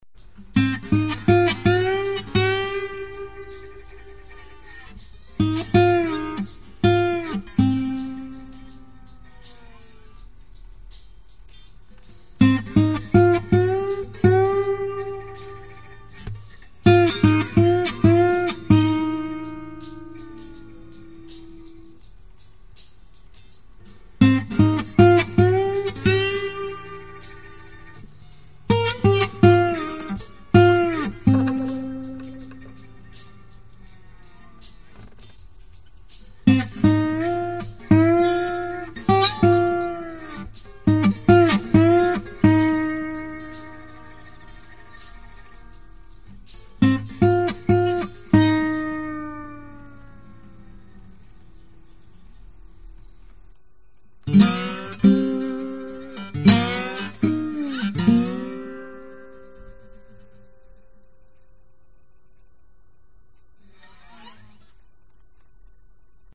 描述：在马丁原声吉他上的未经压缩的原始滑音吉他。
Tag: 吉他 循环 C_minor 原声 幻灯片吉他